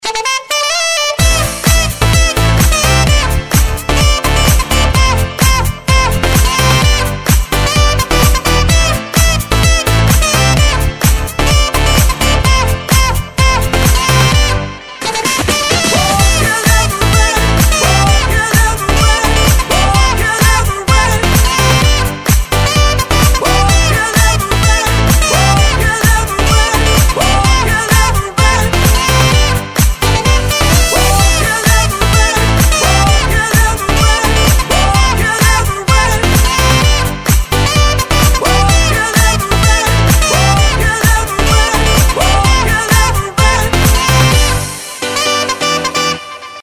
• Качество: 128, Stereo
позитивные
веселые